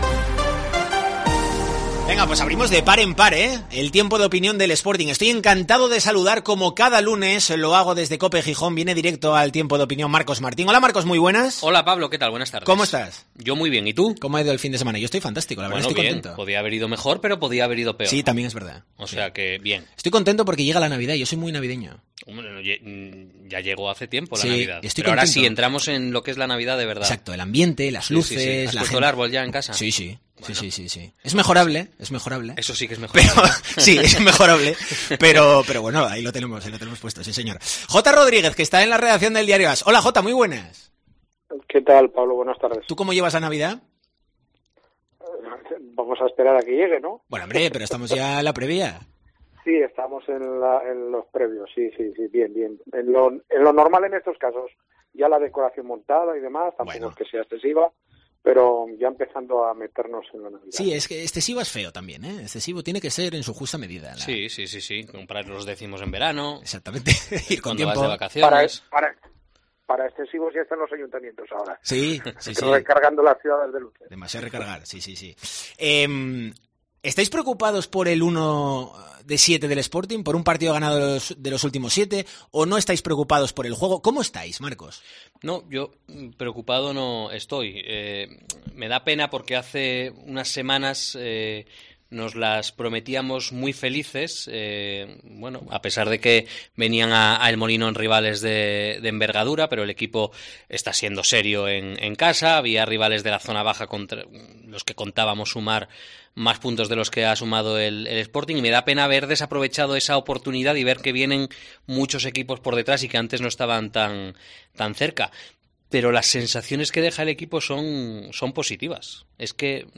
'Tiempo de Opinión rojiblanco', en COPE Asturias En el capitulo de este lunes en Deportes COPE Asturias hemos debatido en el 'Tiempo de Opinión rojiblanco' acerca de la mejoría en el juego del Sporting y la mala racha de resultados, con solo un triunfo en los últimos siete partidos.